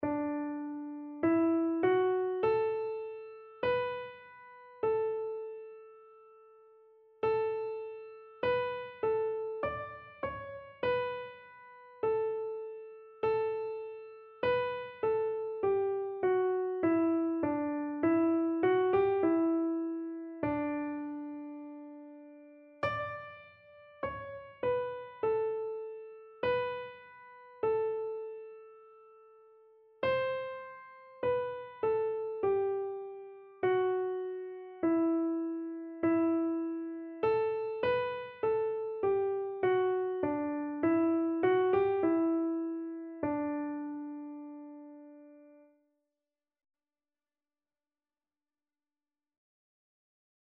Christian Christian Keyboard Sheet Music Come Down, O Love Divine
Free Sheet music for Keyboard (Melody and Chords)
D major (Sounding Pitch) (View more D major Music for Keyboard )
4/4 (View more 4/4 Music)
Keyboard  (View more Easy Keyboard Music)